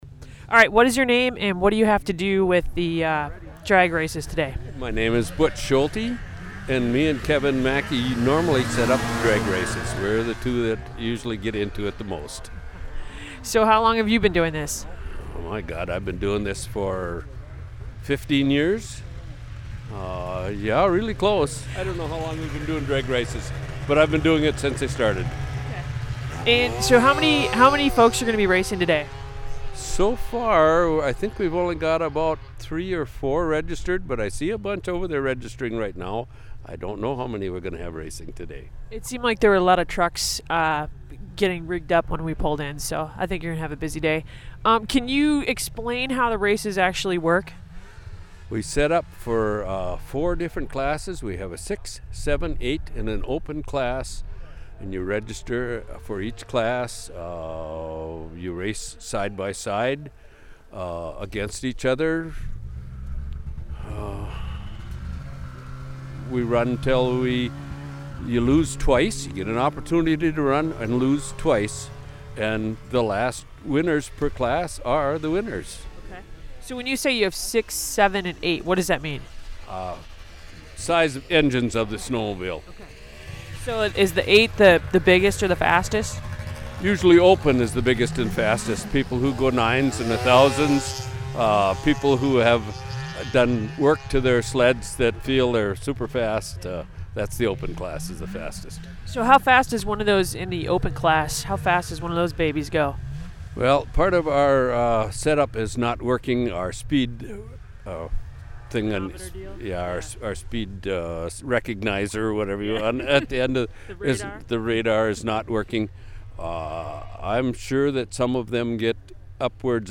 The Cook County Ridge Riders Snowmobile Club hosted an afternoon of drag racing on Devils Track Lake Saturday afternoon.
Out n About - Snowmobile Drag Races.mp3